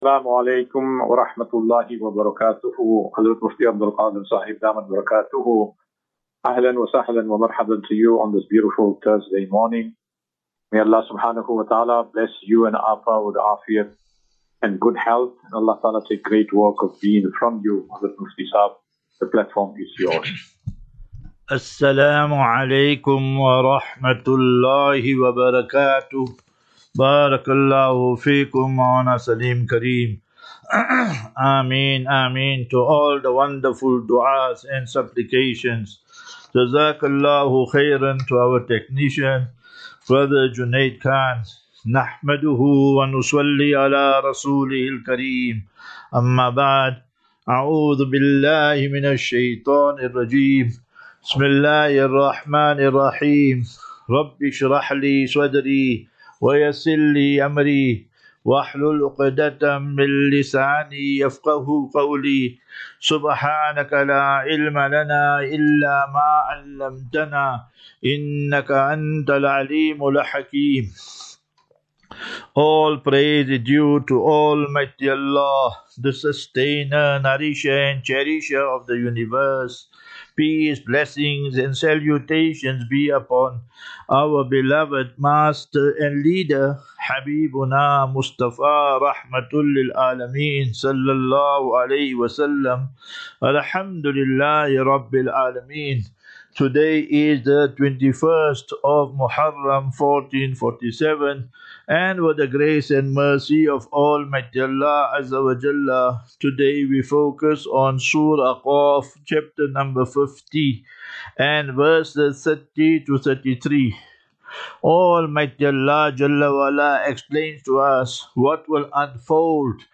17 Jul 17 July 2025. Assafinatu - Illal - Jannah. QnA